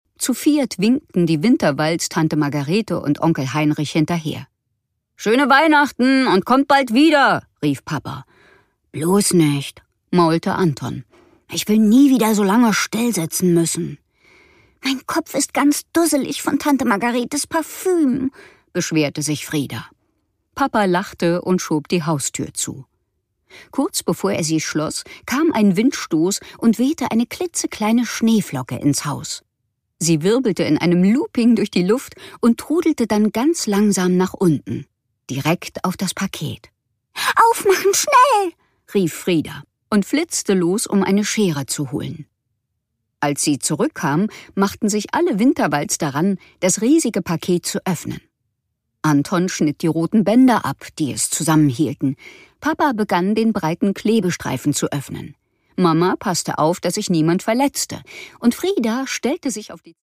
Produkttyp: Hörbuch-Download
Ihre klugen und lebhaften Interpretationen und ihre große Stimmenvielfalt machen jedes ihrer Hörbücher zu einem Erlebnis.